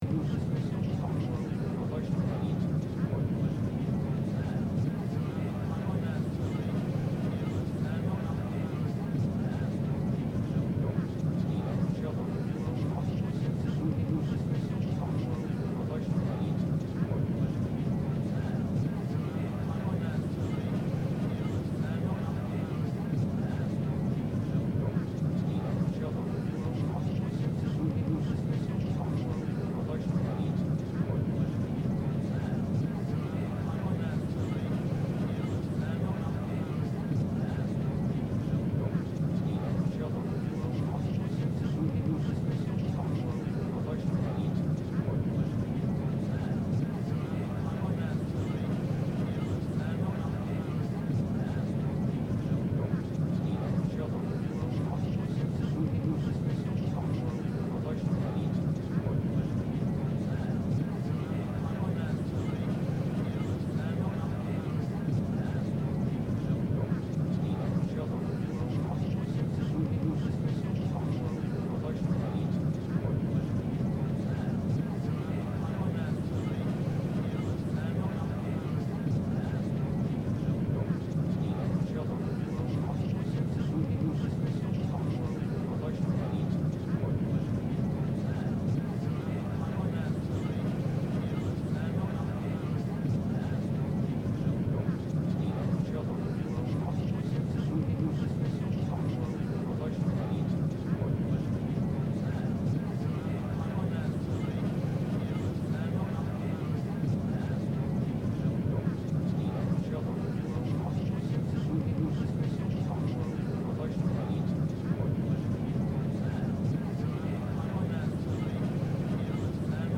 merchantship.ogg